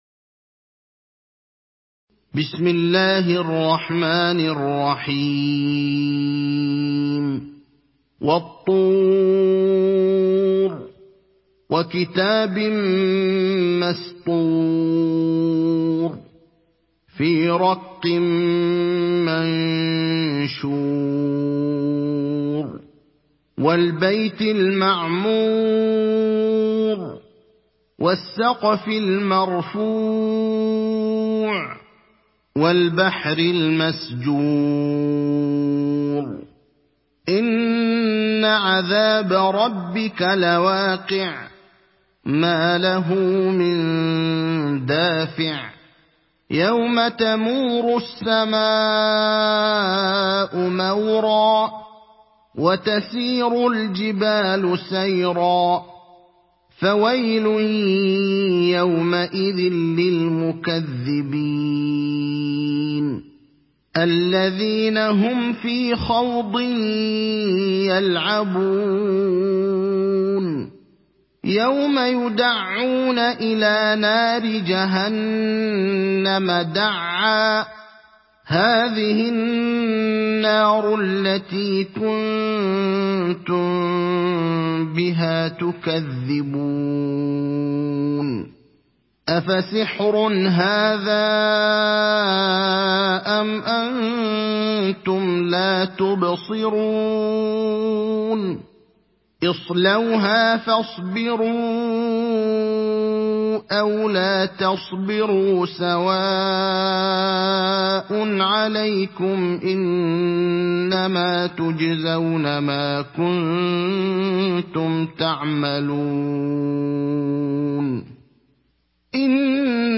تحميل سورة الطور mp3 بصوت إبراهيم الأخضر برواية حفص عن عاصم, تحميل استماع القرآن الكريم على الجوال mp3 كاملا بروابط مباشرة وسريعة